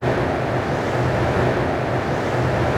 tunnelMiddle.wav